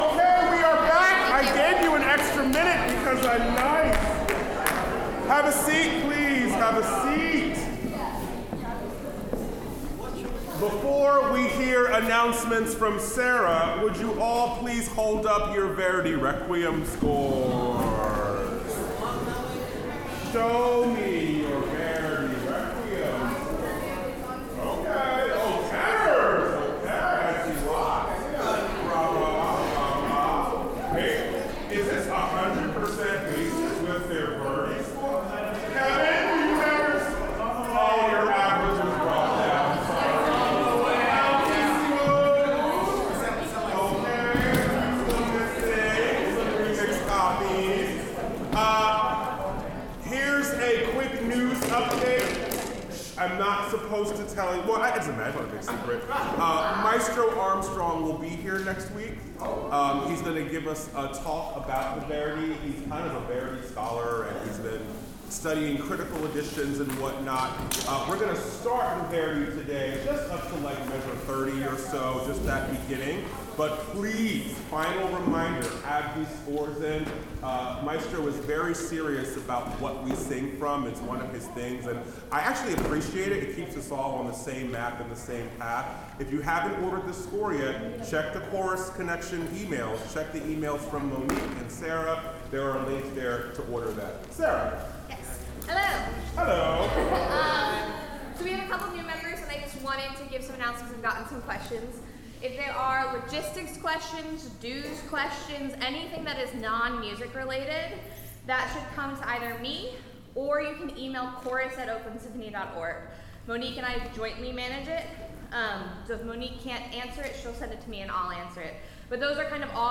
OSC Rehearsal, February 12, 2025
06:34 Gaba Ze Ka Ka and other warm-ups